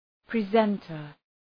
{‘prezəntər}
presenter.mp3